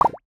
UIClick_Bubbles Splash 02.wav